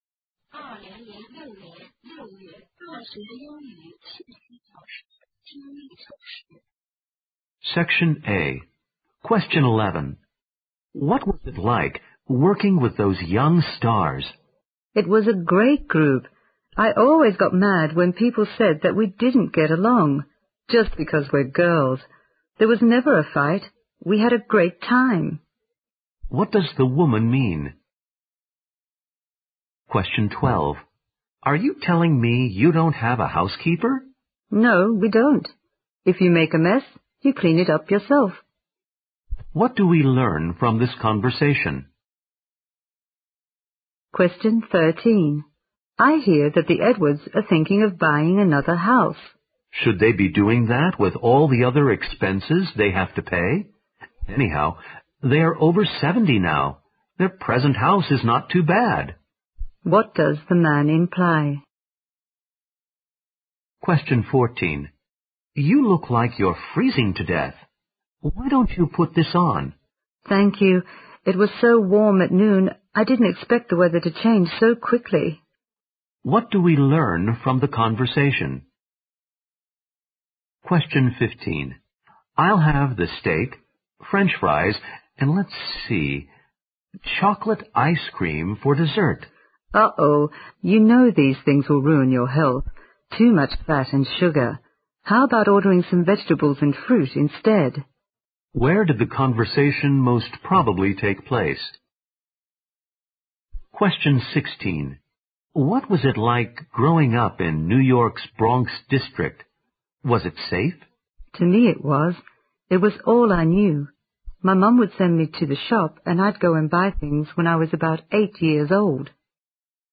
Directions: In this section, you will hear 8 short conversations and 2 passages.
Both the conversation and the questions will be spoken only once. After each question there will be a pause.